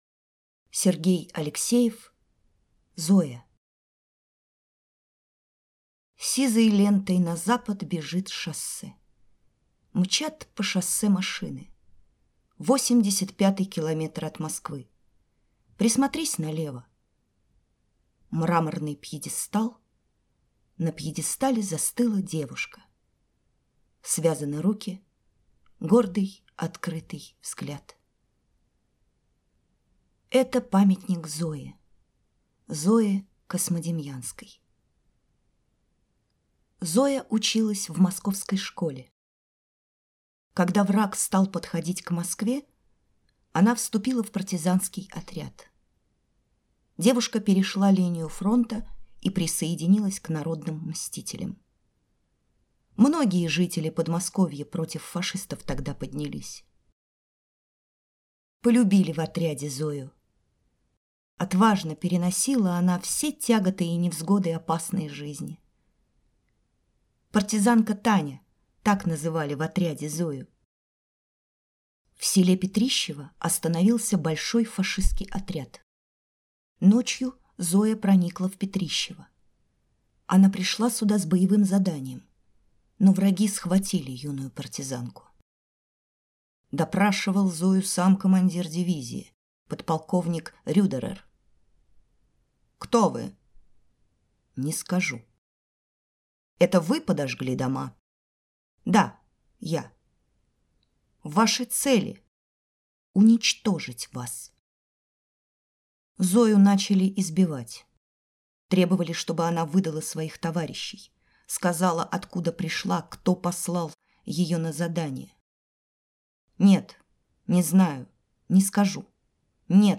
Аудиорассказ «Зоя»